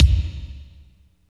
29.05 KICK.wav